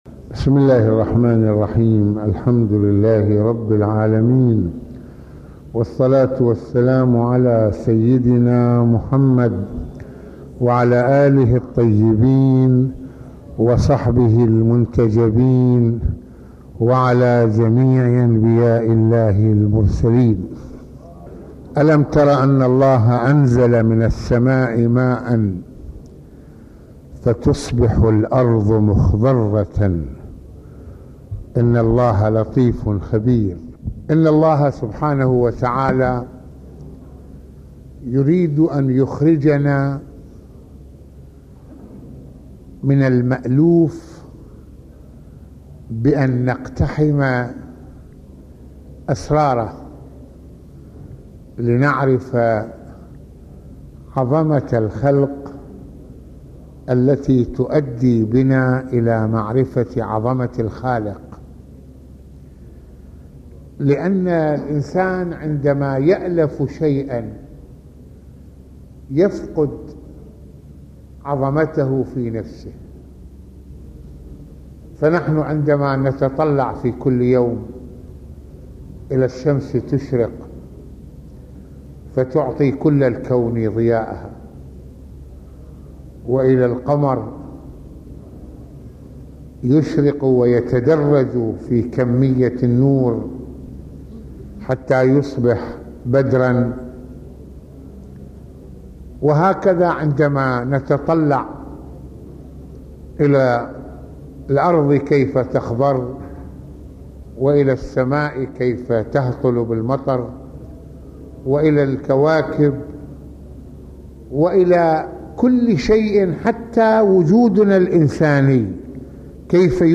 - يتحدث المرجع السيد محمد حسين فضل الله (رض) في هذه المحاضرة القرآنية عن أبعاد الخطاب الإلهي الداعي إلى معرفة عظمة عبر تأمل وتدبر آياته في الكون بوعي لفهم أسرارها بدقة وما في عظمة الله من حقيقة تزيدنا إيماناً وانفتاحا عليه ونفيا ً لكل أشكال التعظيم لغيره تعالى ، وما على الإنسان سوى تحريك بصيرته في مواطن العظمة ليعرف طريق الحق وما يتصل بذلك من عناوين ...